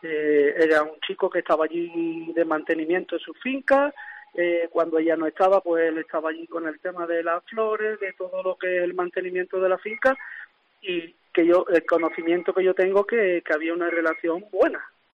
José Carlos Sánchez, alcalde de Algar